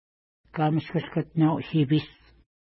ID: 475 Longitude: -62.1195 Latitude: 53.5267 Pronunciation: ka:məskuskətna:w-ʃi:pi:s Translation: Unknown Feature: river Explanation: Named in reference to lake Kamashkushkatinau-nipi (no 474) from which if flows.